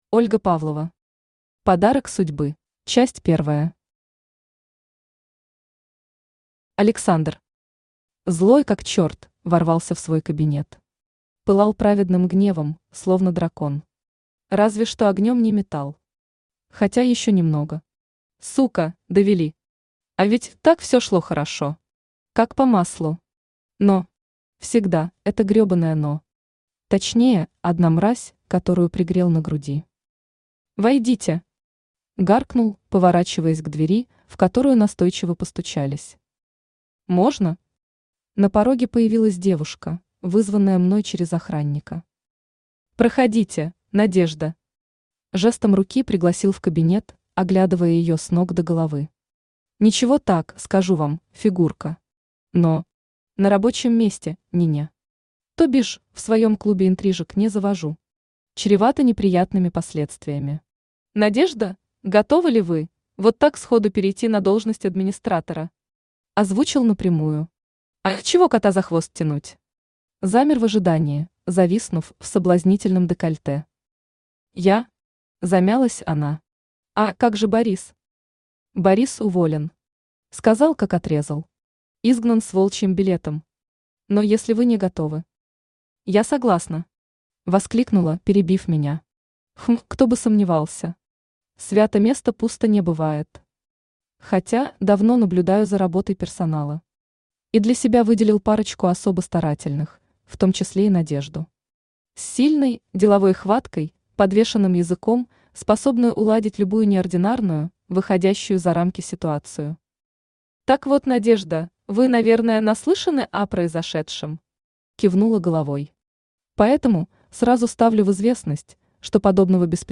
Аудиокнига Подарок судьбы | Библиотека аудиокниг
Aудиокнига Подарок судьбы Автор Ольга Анатольевна Павлова Читает аудиокнигу Авточтец ЛитРес.